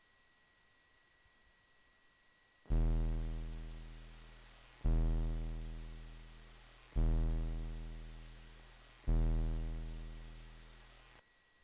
The combination of low frequency and the triangle wave makes very evident the presence of aliasing in some of them.
test-fpgasid-6581-dac-linear.mp3